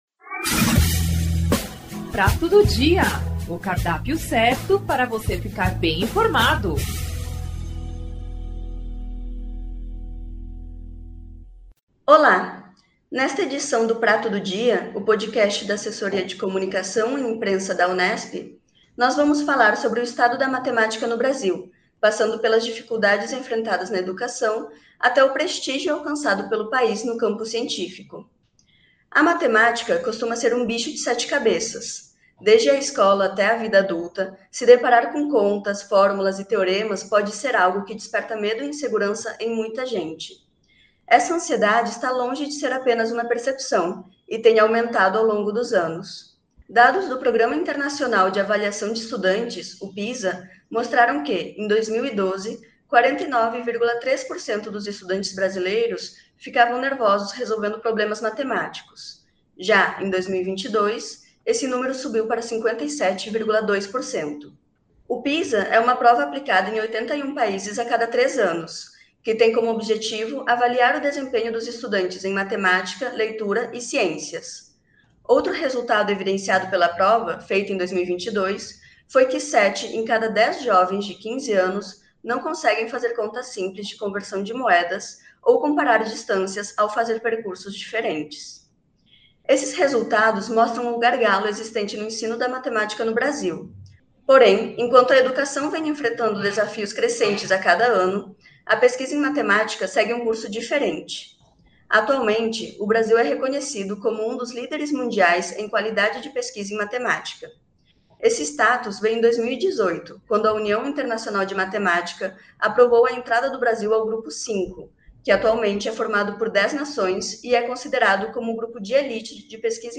Marco Viana é o entrevistado desta edição do Podcast Prato do Dia. O bate-papo tem como foco analisa diferentes aspectos do "estado da matemática" no Brasil, ou seja, desde as dificuldades enfrentadas na educação, na aprendizagem dos alunos até à relevância no campo científico.
O “Prato do Dia”, Podcast da Assessoria de Comunicação e Imprensa da Reitoria da Unesp é um bate-papo e uma troca de ideias sobre temas de interesse da sociedade. De maneira informal debateremos tópicos atuais, sempre na perspectiva de termos o contra-ponto, o diferencial.